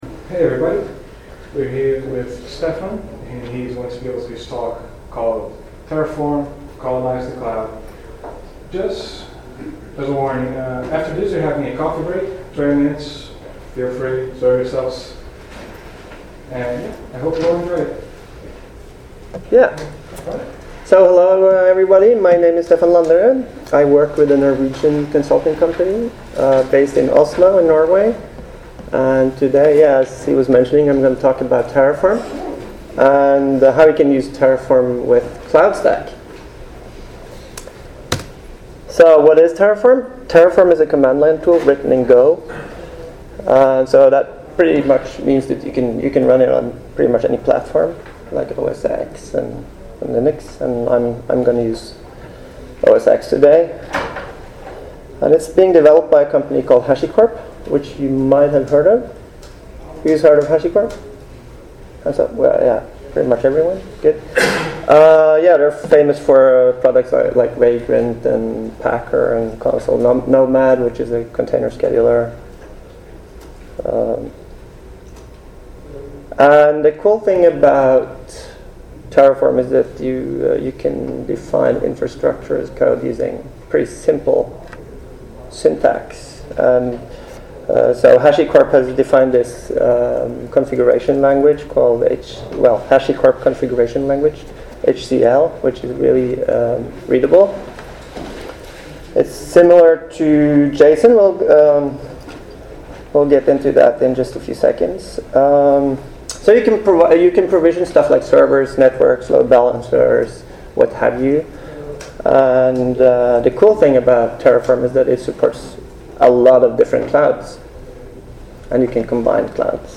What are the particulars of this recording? ApacheCon Miami 2017 – Terraform – Colonise the Cloud! Bekk Consulting AS Cloudstack Collaboration Conference